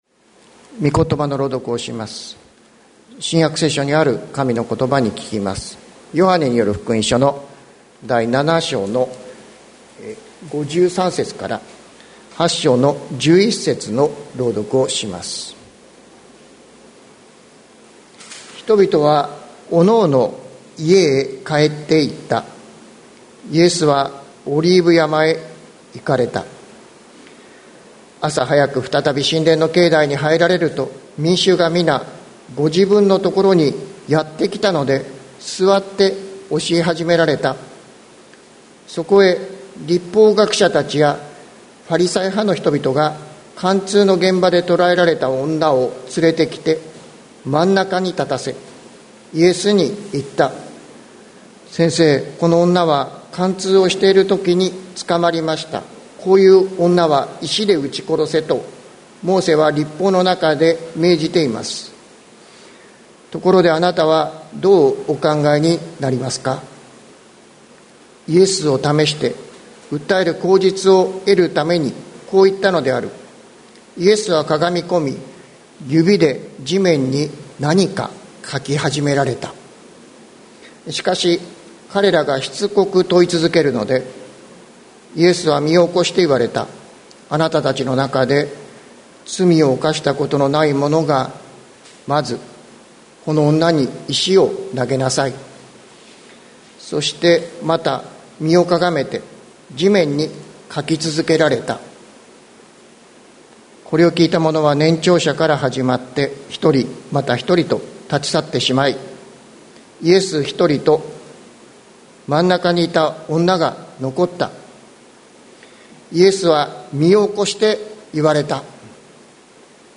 2022年07月24日朝の礼拝「これが罪の赦し」関キリスト教会
説教アーカイブ。